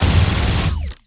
Added more sound effects.
GUNAuto_Plasmid Machinegun C Burst_04_SFRMS_SCIWPNS.wav